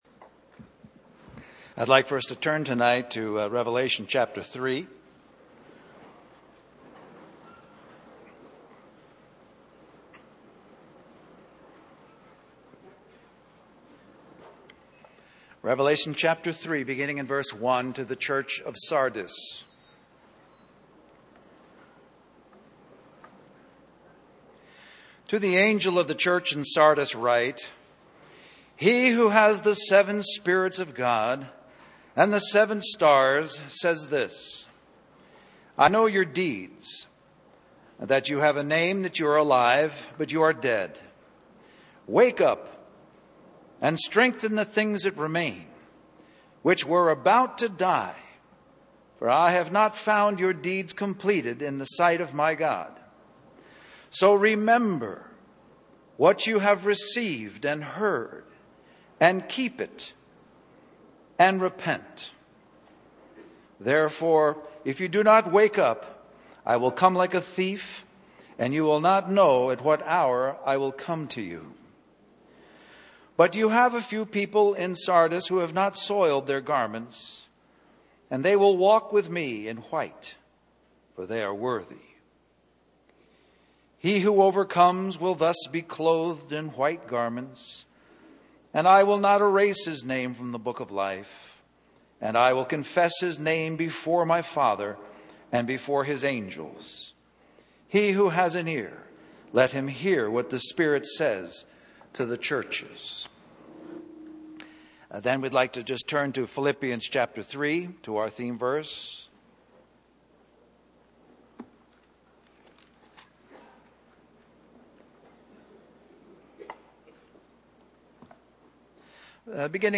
2008 Christian Family Conference Stream or download mp3 Summary Do we remember our first encounter with Church life or is our independent life getting in the way of remembering the Lord and living in true body life.